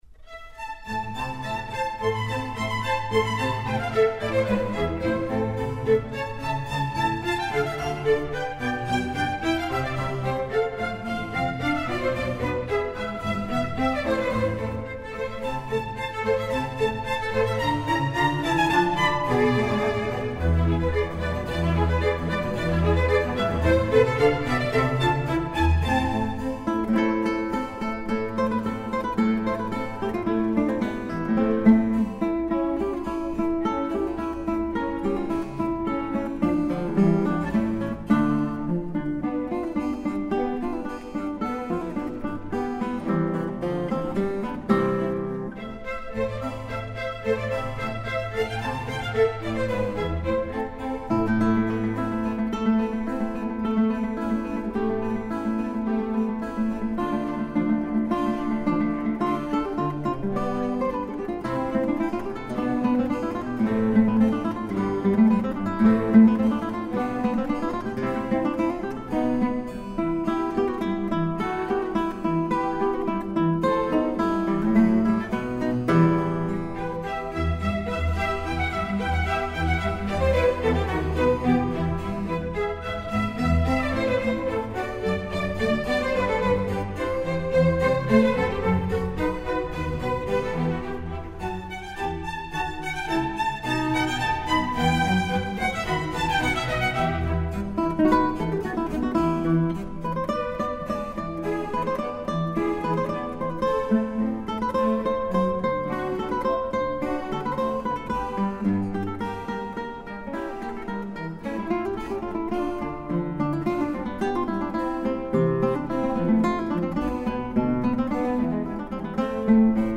arr. for Guitar & trings